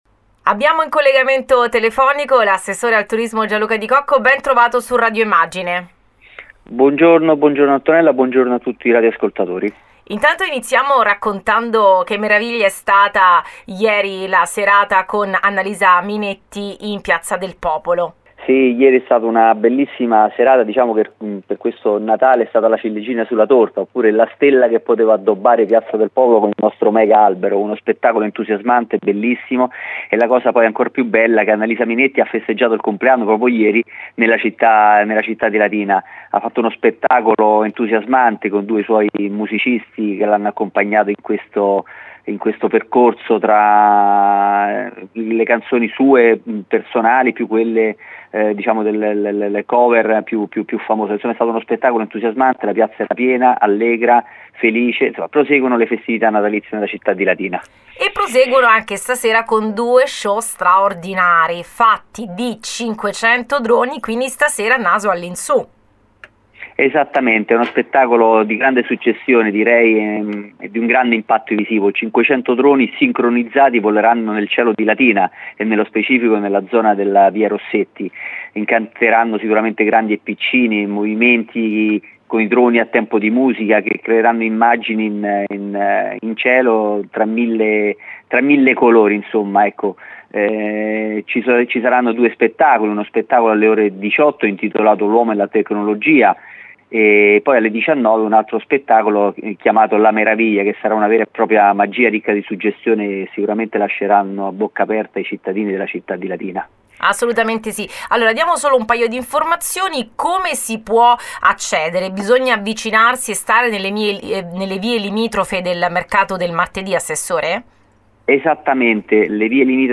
Abbiamo raggiunto telefonicamente l’Assessore al Turismo Gianluca Di Cocco con il quale siamo partiti dal successo del concerto di Annalisa Minetti del 27 Dicembre in Piazza del Popolo, passando per lo Show di Droni di questa sera per arrivare  al Capodanno e alla discesa della Befana.